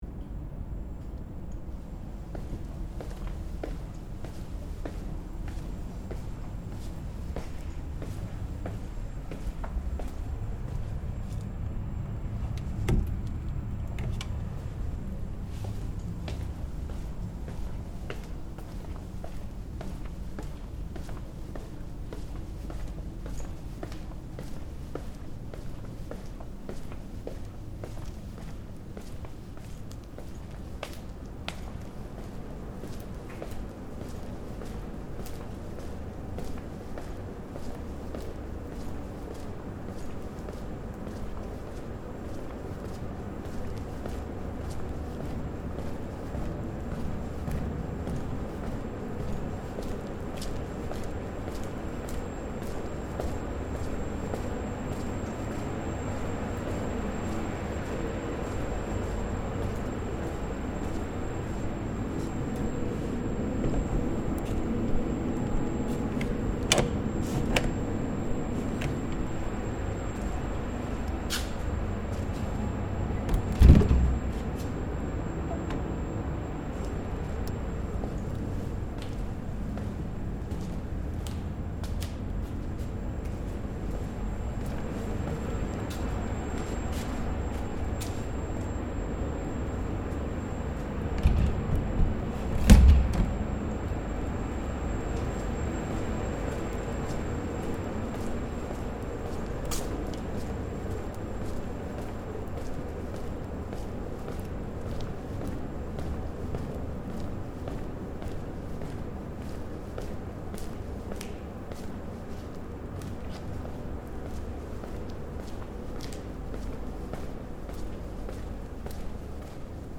Soundscape: La Silla ESO 3.6-metre-telescope 2nd floor
Walking all the way around the second floor of the ESO 3.6-metre telescope building.
Soundscape Stereo (wav)